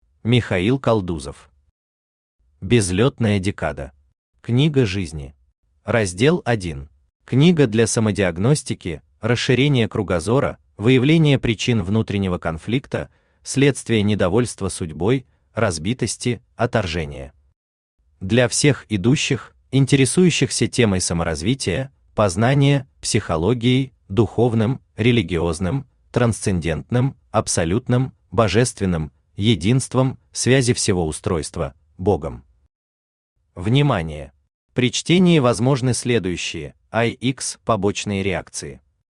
Аудиокнига Безлетная декада. Книга жизни | Библиотека аудиокниг
Книга жизни Автор Михаил Константинович Калдузов Читает аудиокнигу Авточтец ЛитРес.